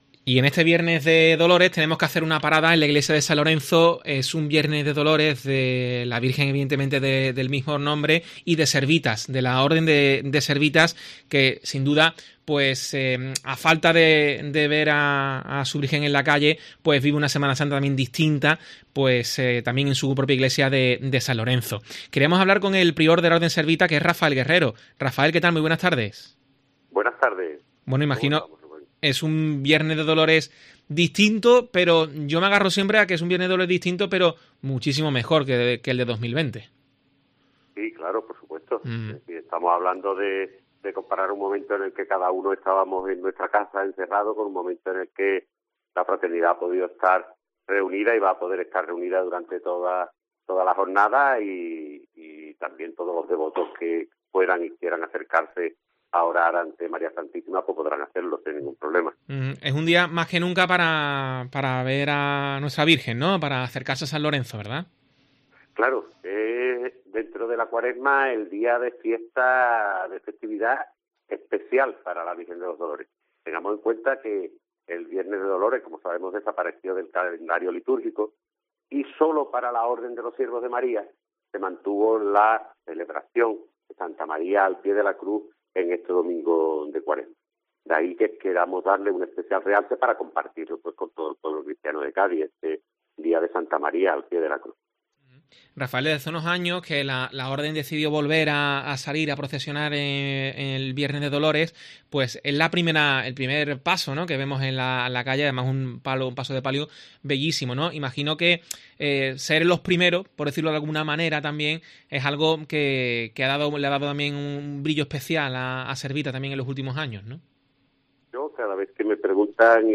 Cádiz